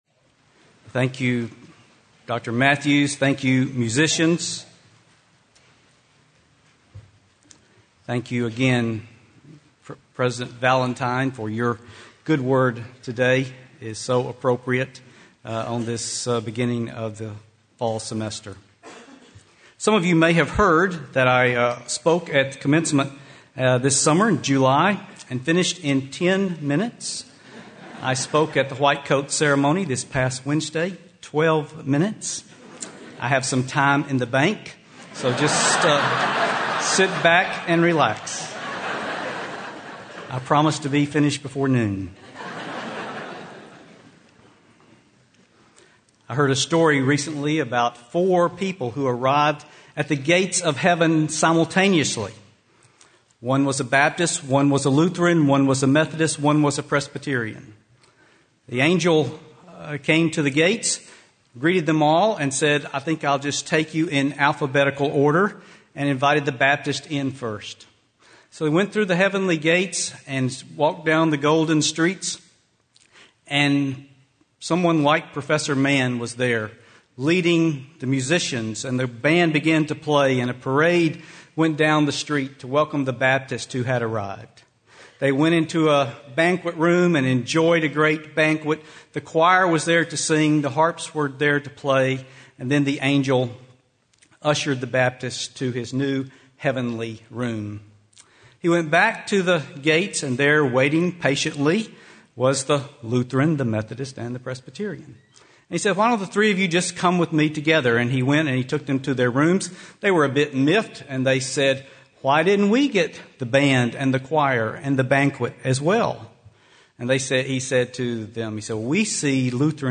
Convocation Chapel